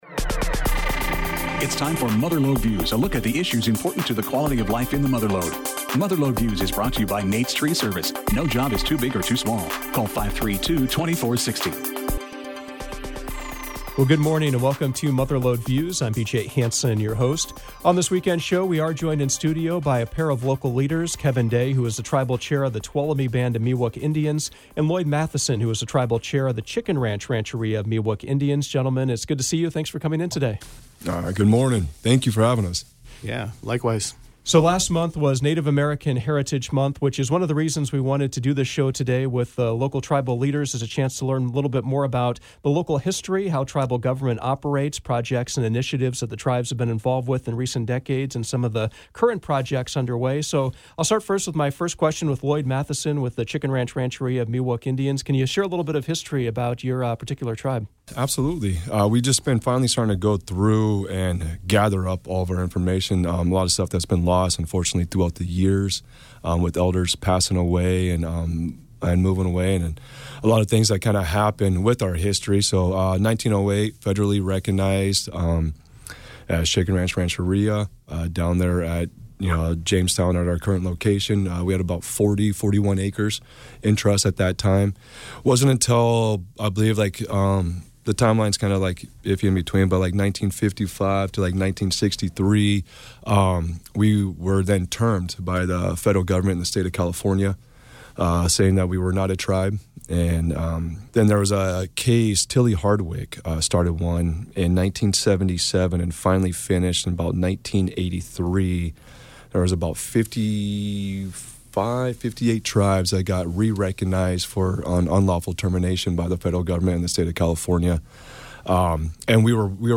Mother Lode Views featured a conversation with Kevin Day, Chair of the Tuolumne Band of Me Wuk Indians, and Lloyd Mathiesen, Chair of the Chicken Ranch Rancheria of Me Wuk Indians.